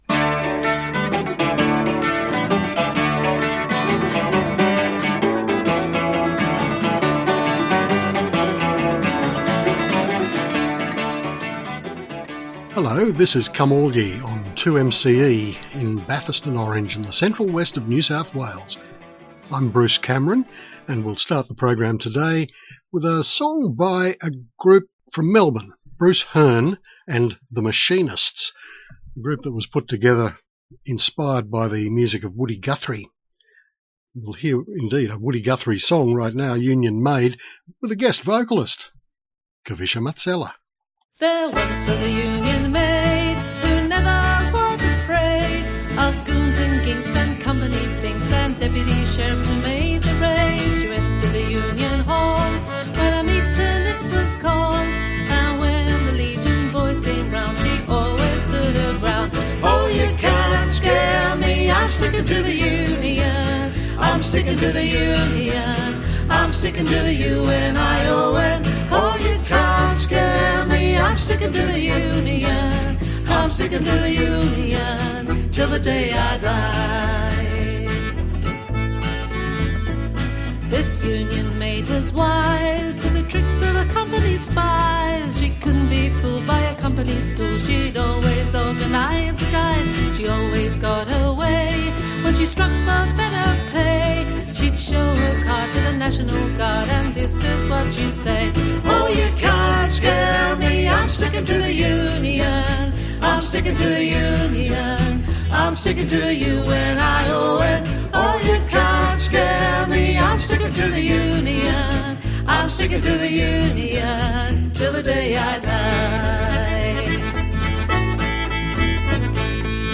This is a delightful album of traditional folk songs, presented expressively, not encumbered by any unnecessarily 'clever' accompaniment and almost all with a gentle warmth, the vocals supported by excellent guitar and mandolin.